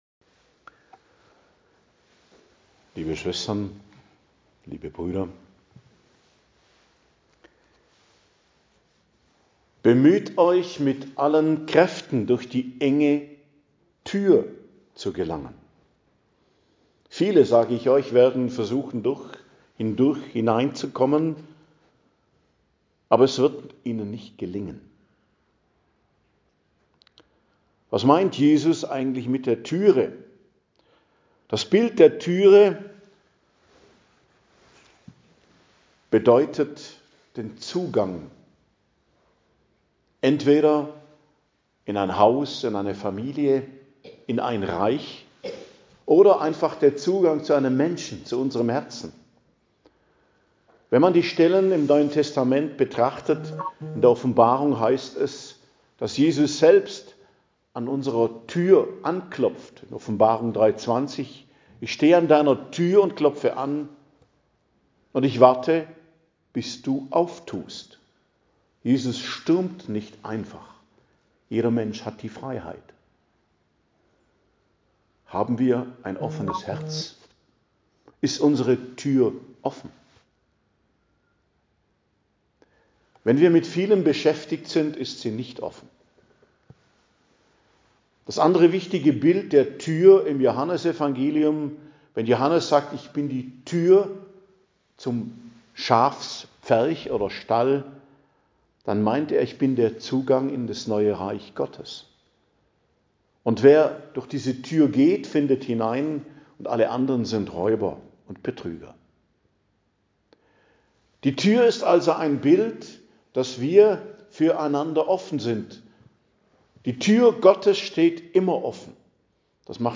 Predigt zum 21. Sonntag i.J., 24.08.2025